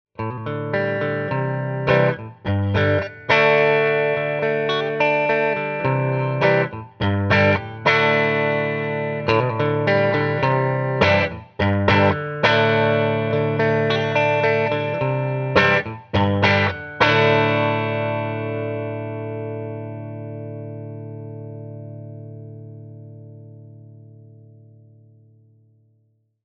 50R and 50B used together (middle position) 50R and 52T used together (middle position)